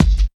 85 KICK.wav